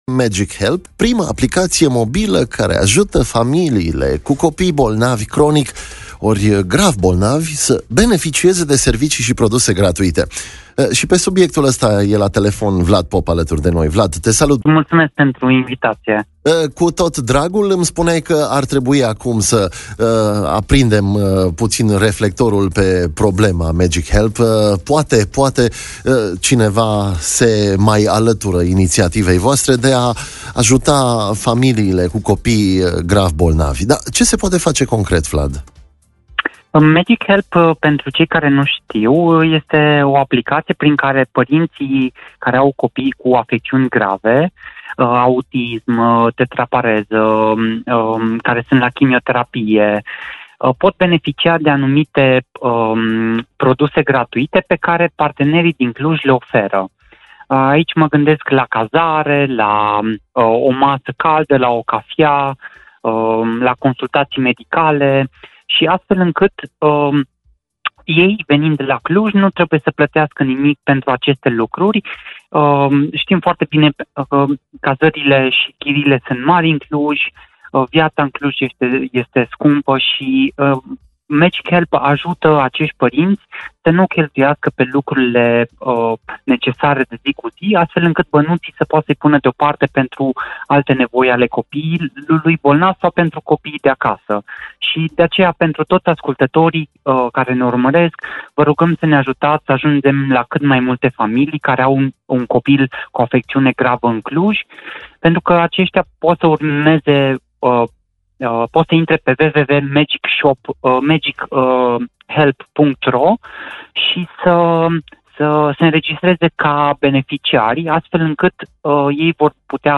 la emisiunea WakeUp Cluj.